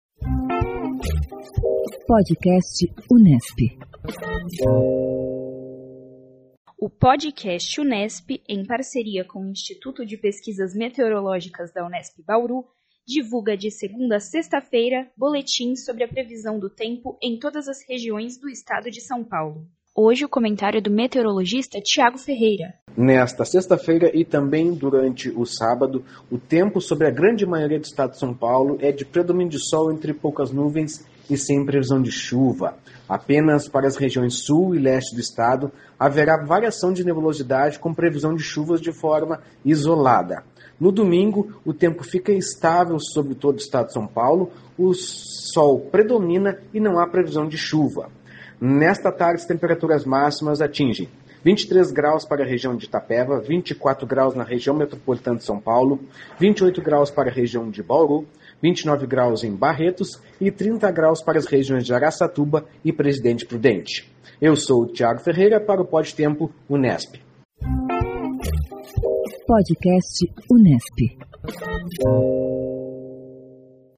O Podcast Unesp, em parceria com o Instituto de Pesquisas Meteorológicas da Unesp, divulga diariamente boletins sobre a previsão do tempo em todas as regiões do Estado de São Paulo.